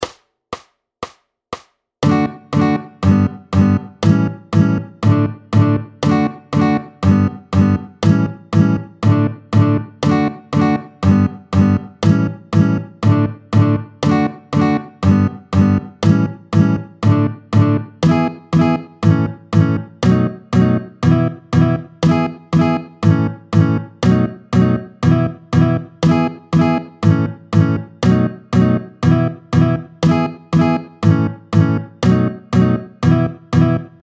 Vite, tempo 120